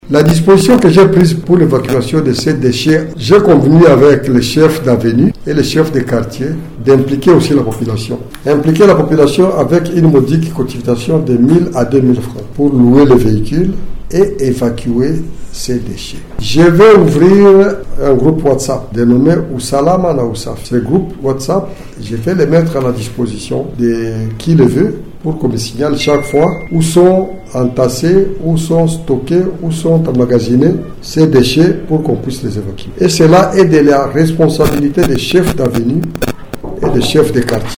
Précision du maire de Bukavu, Ladislas Muganza dans un entretien avec la presse locale mardi 4 mars 2025 à son bureau.